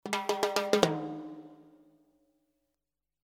Timbales fills in 150 bpm
If you are already a member in ower site,just sign in and download for free,28 fills The timbales are with light reverb and they are in 150 bpm.
This package contains real timbales fills playing a variety of fills in 150 bpm.
The timbales were recorded using “ AKG C-12” mic. The timbales were recorded mono but the files are stereo for faster workflow. The reverb is stereo on the mono timbales. And There is only light and perfect analog EQ and light compression, giving you The Opportunity to shape the sound you like in your project.